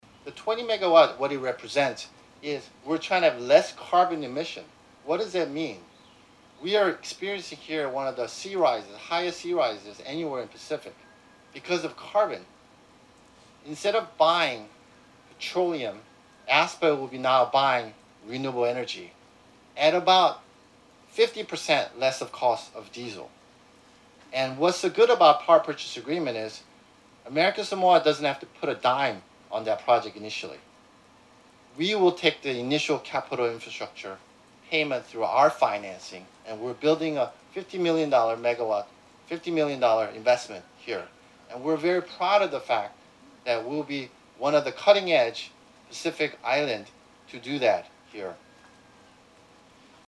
Governor Lemanu P.S. Mauga, Senate President Tuaolo M. Fruean, members of the Senate and House of Representatives, and officials of Eastern Power Solutions and ASPA representatives, braved the pouring rain to witness the ceremony.